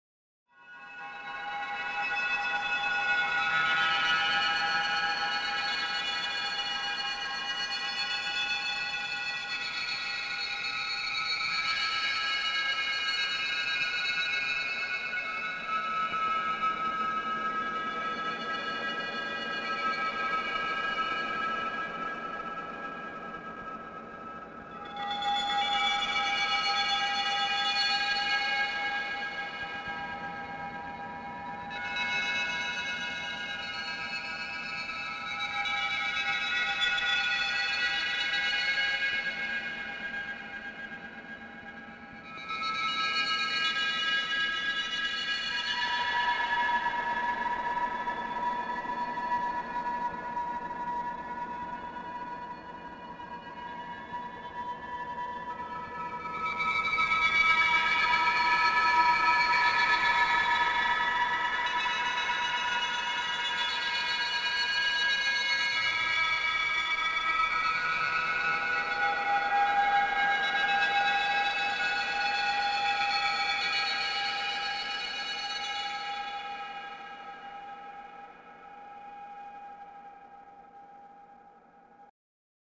TV spherical sounds